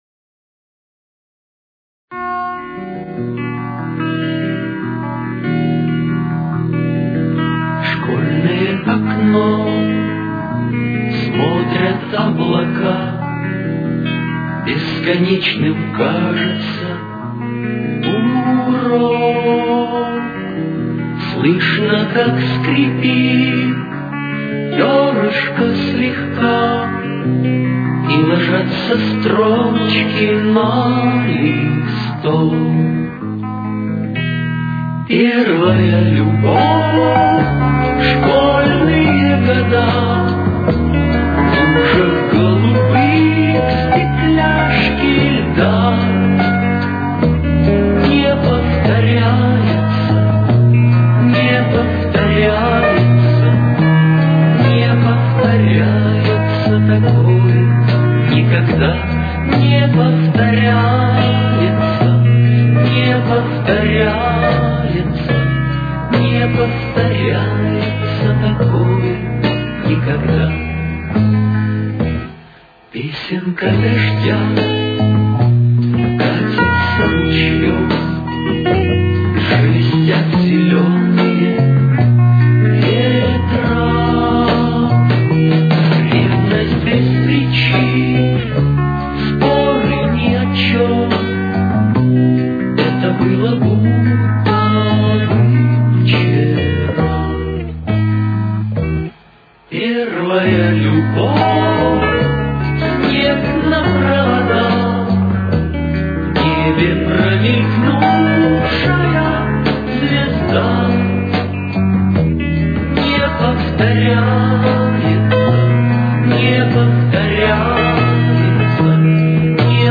Тональность: Ми-бемоль минор. Темп: 75.